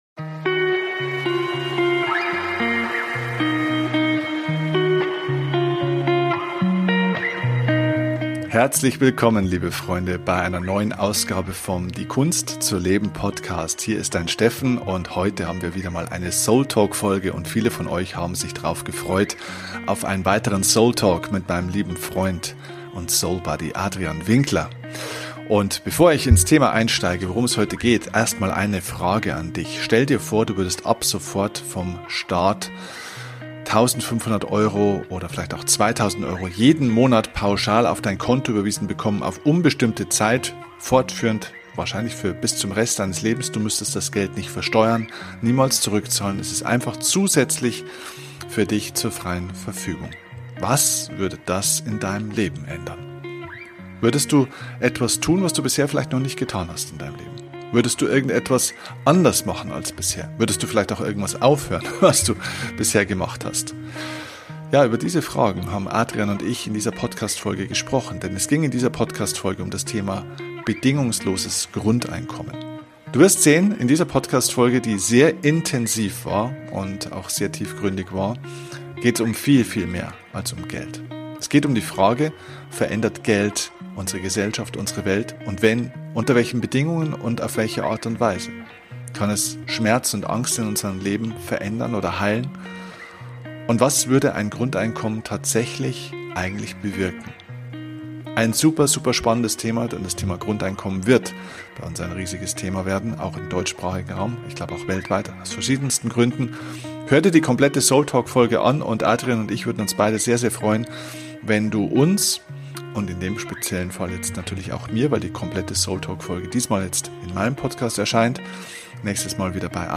Wie immer bei diesem Format verzichten wir auf ein Skript.